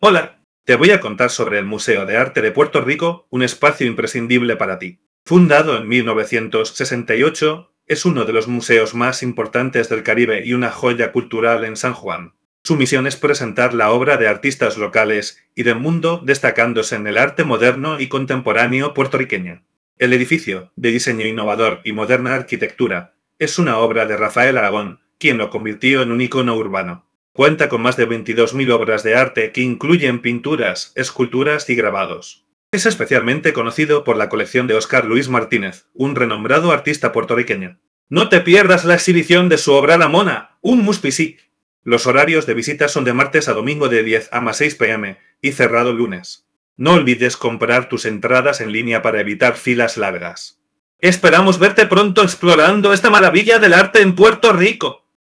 karibeo_api / tts / cache / 81e562036a43d6c0fead97fadd0051e6.wav